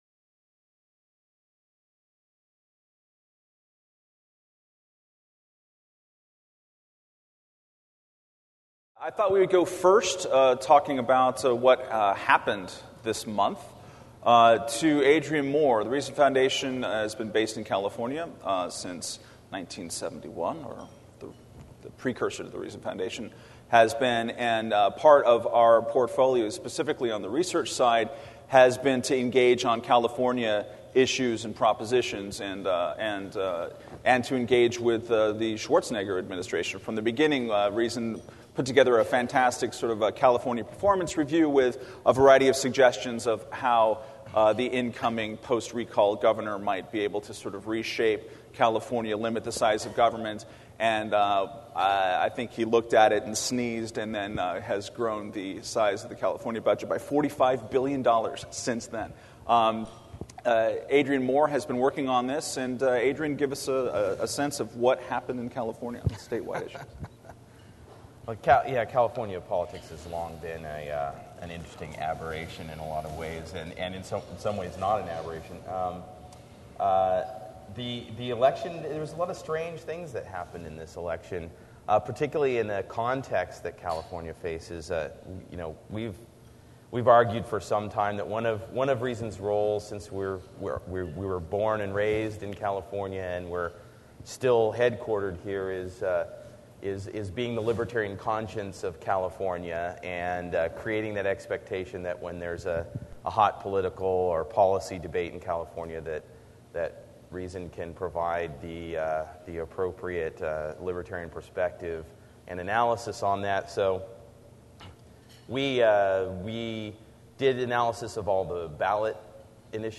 At Reason Goes Hollywood, our 40th anniversary bash held November 14-15, 2008 in Los Angeles